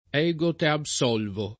vai all'elenco alfabetico delle voci ingrandisci il carattere 100% rimpicciolisci il carattere stampa invia tramite posta elettronica codividi su Facebook ego te absolvo [lat. $g o t H ab S0 lvo ] frase («io t’assolvo»)